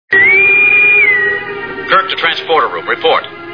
Star Trek TV Show Sound Bites